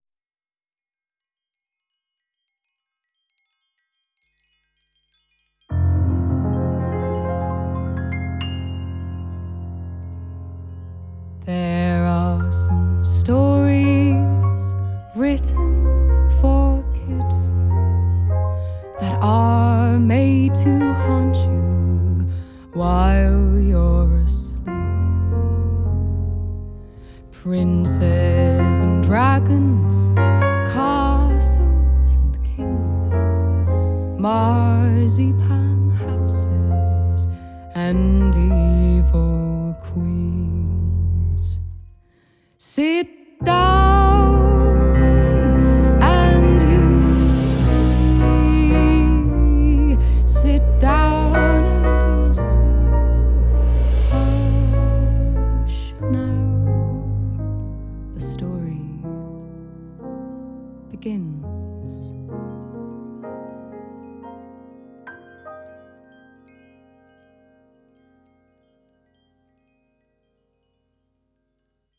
Jazz meets Folk music.